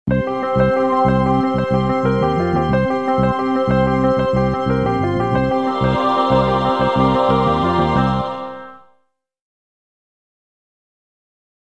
Звук оглушающего старта